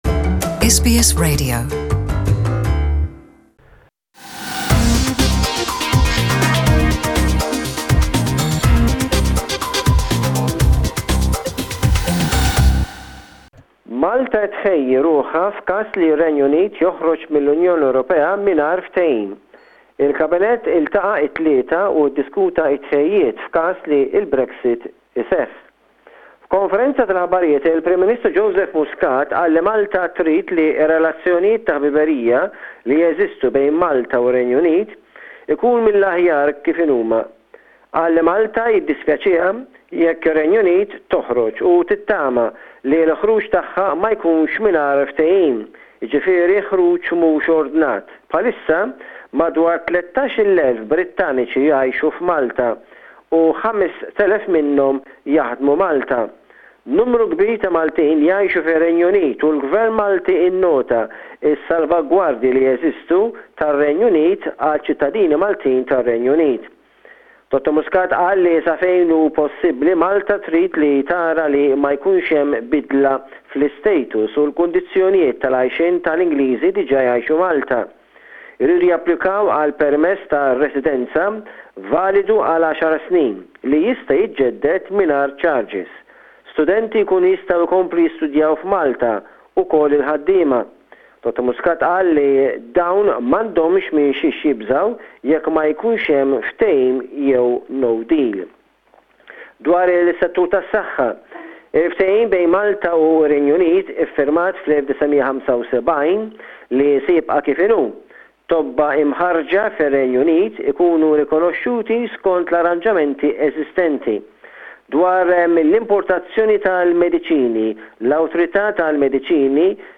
Maltese correspondent in Malta